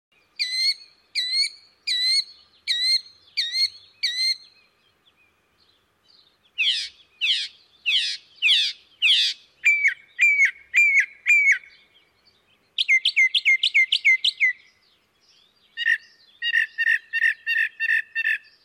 Northern Mockingbird
• The Northern Mockingbird is a loud and persistent singer.
Bird Sound
Song is a series of varied phrases, with each phrase repeated many times in a row. Includes much mimicry of other bird songs and calls. Call a harsh dry "chew."
NorthernMockingbird.mp3